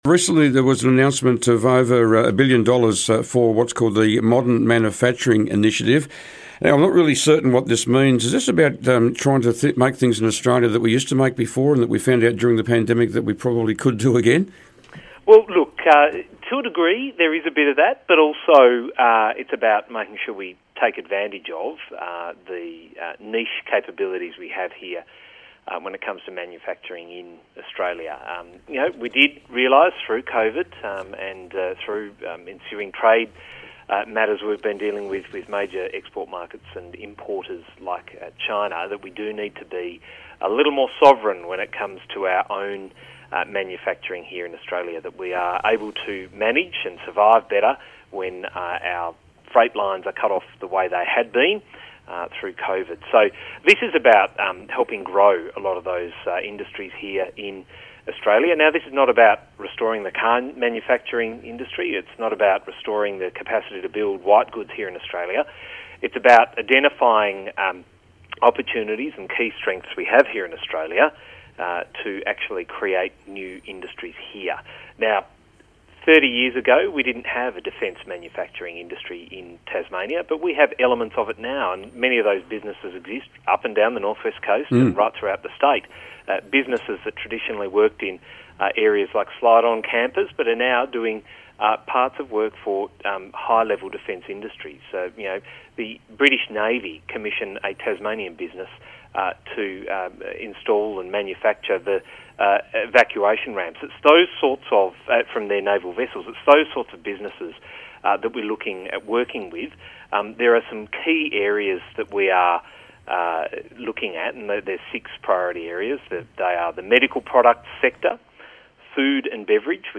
Senator Jonathon Duniam stepped up onto the Pollies Perch today.